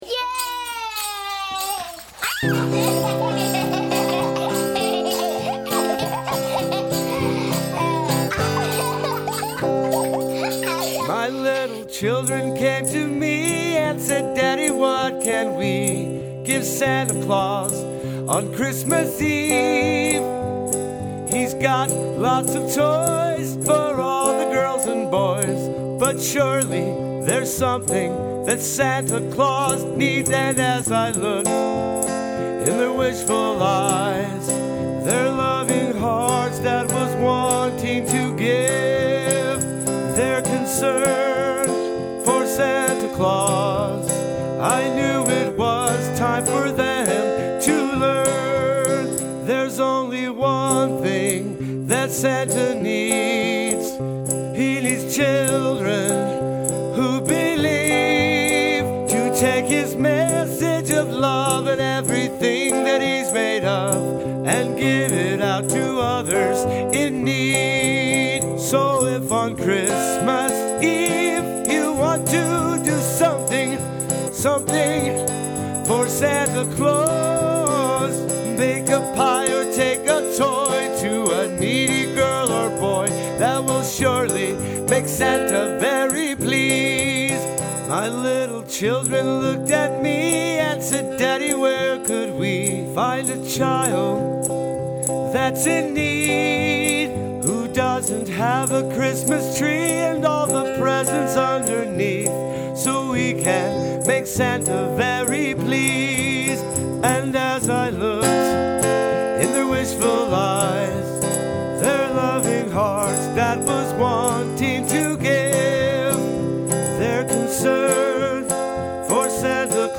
Santa’s Gift (Christmas Song